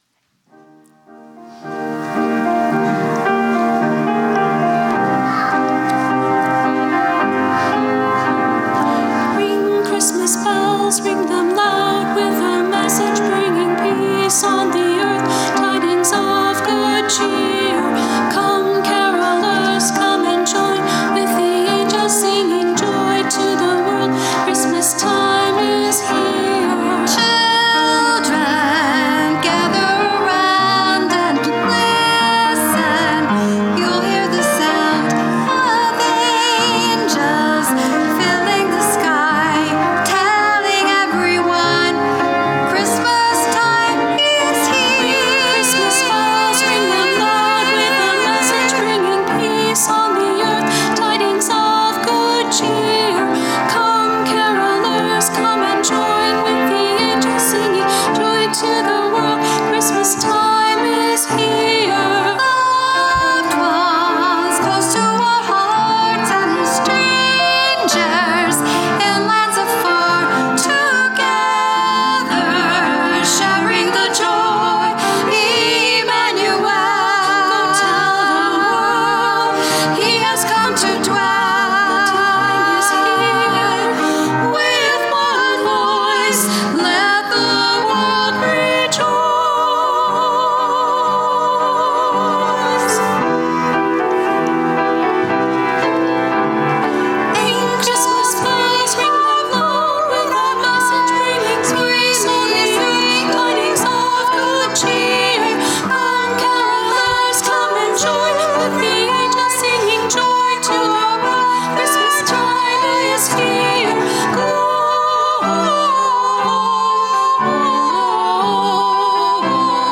Christmas Eve Candlelight Service